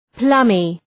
Προφορά
{‘plʌmı}